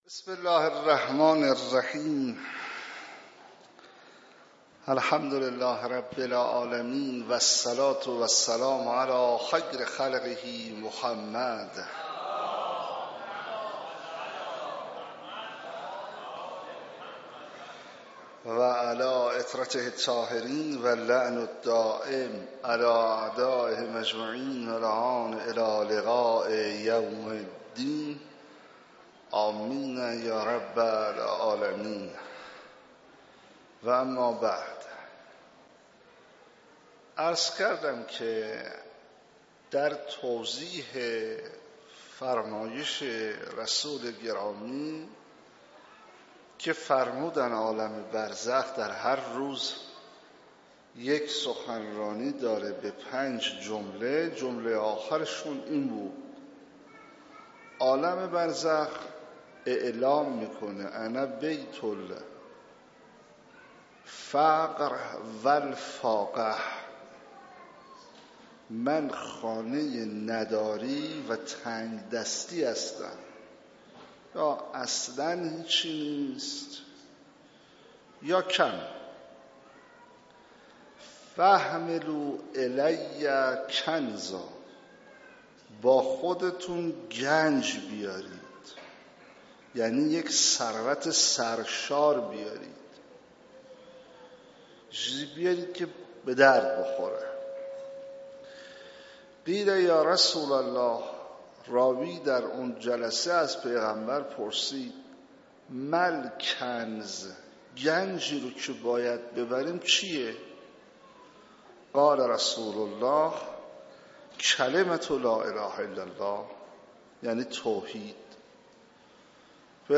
شب سی ام رمضان 96 - حسینیه حق شناس - سخنرانی